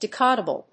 decodable.mp3